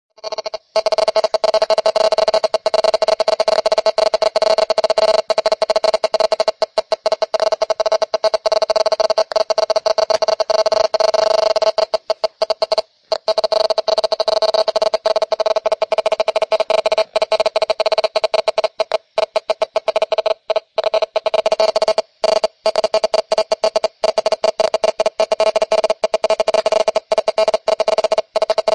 描述：广播演讲者在会议上谈论无线电和数字媒体
Tag: 电台 说话